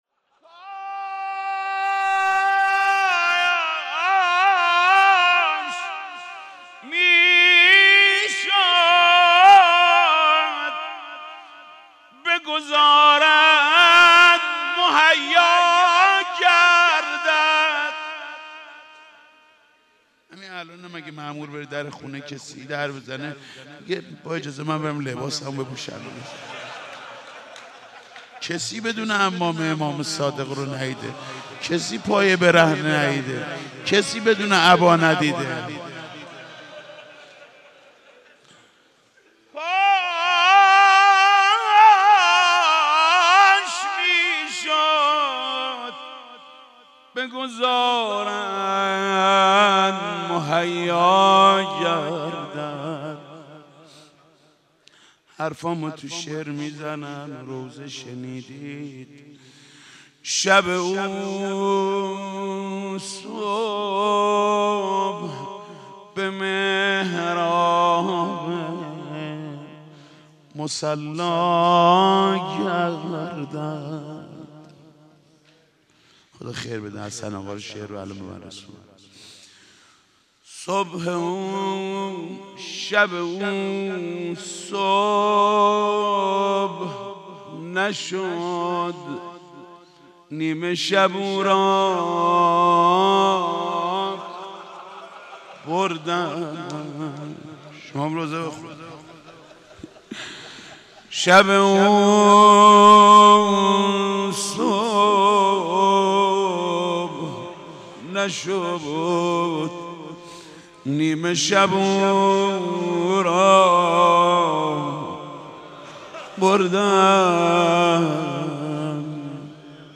روضه2